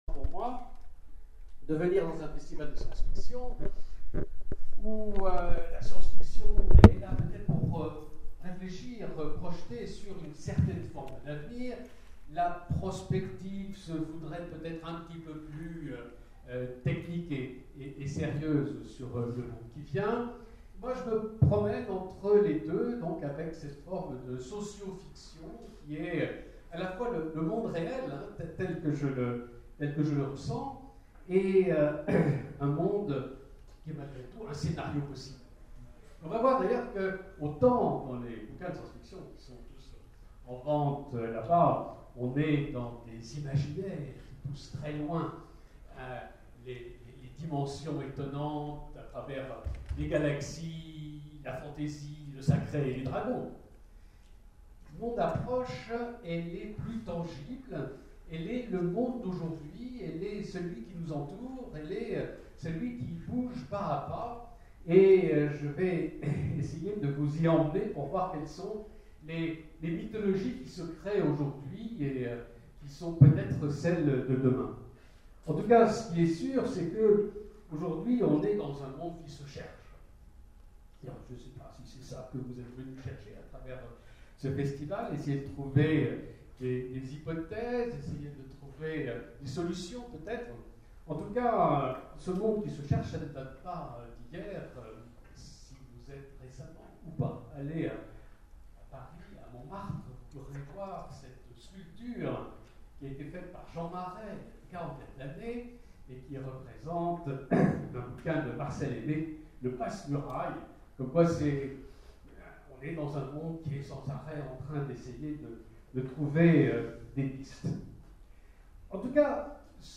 Les intergalactiques 2014 : conférence Les Mythologies du futur : au risque d’un gonzosociologue
Mots-clés SF Conférence Partager cet article